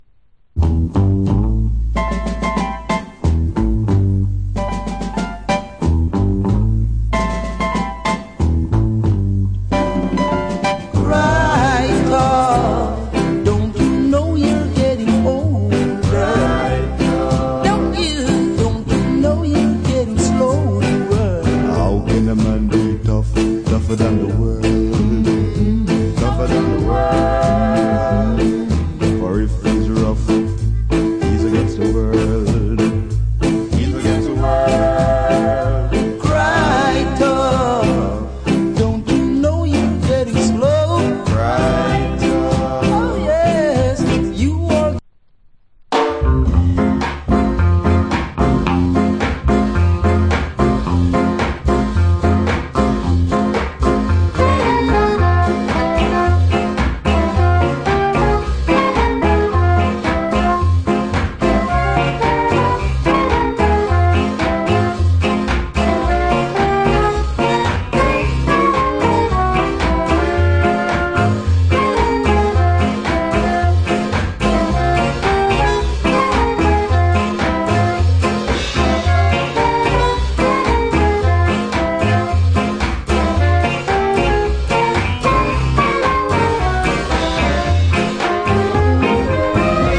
1967 Recording. Alternate Take.